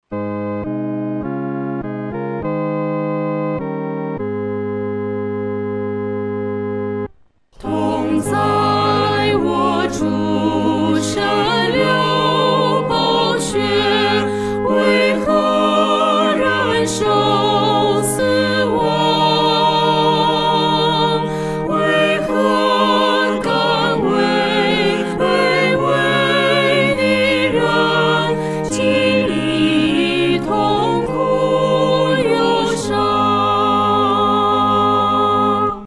四声合唱